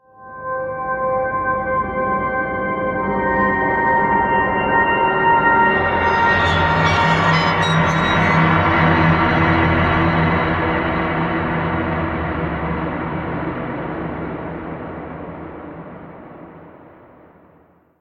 Scary metal atmosphere: